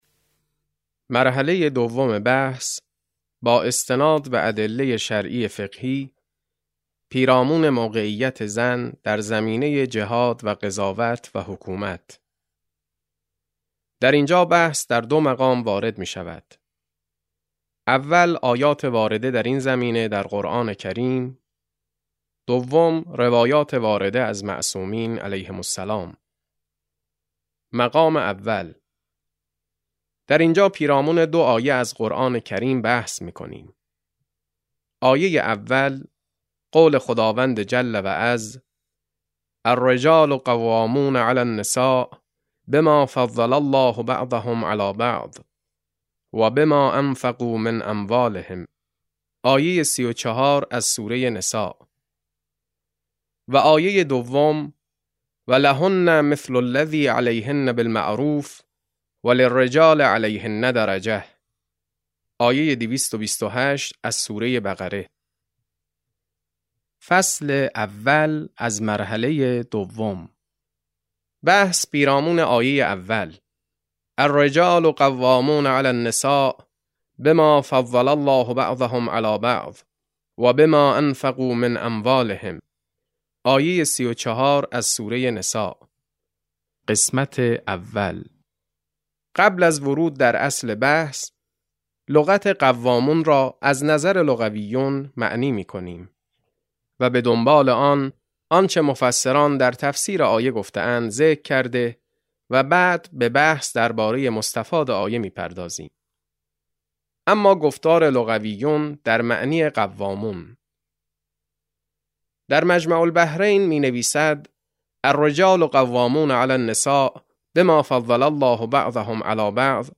رساله بدیعه - مرحله دوم فصل اول - کتاب صوتی - کتاب صوتی رساله بدیعه - بخش4 - علامه طهرانی | مکتب وحی